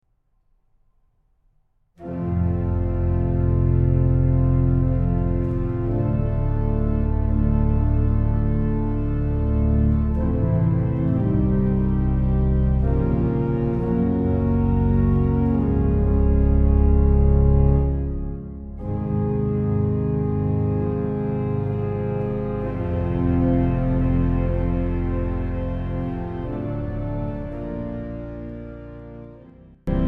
L’organo-orchestra Lingiardi 1877 di S. Pietro al Po in Cremona